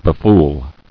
[be·fool]